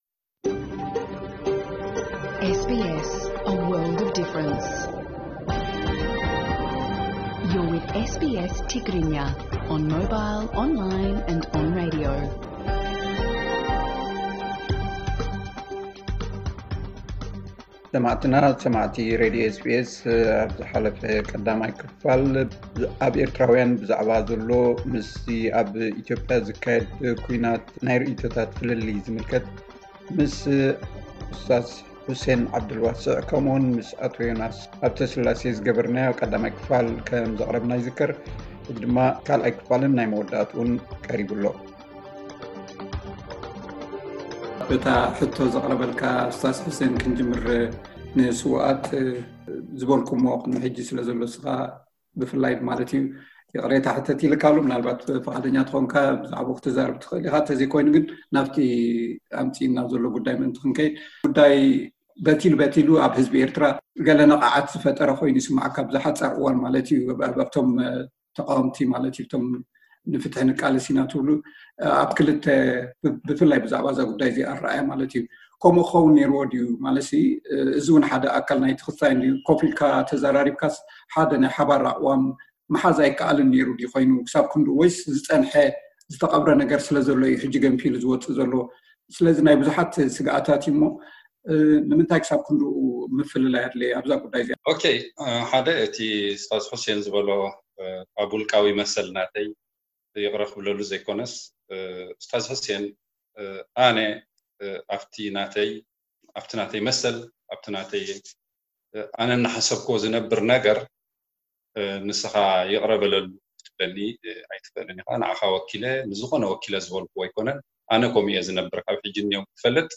ካልኣይ ክፋል ዘተ፡ ኣብ ኢትዮጵያ ብዛዕባ ዝግበር ዘሎ ኲናት ኤርትራዊያን ዝተፈላለዩ ኣረኣእያታት የንጸባርቑ ኣለዉ።